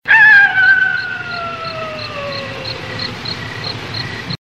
COYOTE HOWLS.mp3
A coyote howling in the wilderness of Tucson Arizona.
coyote_howls_v4d.ogg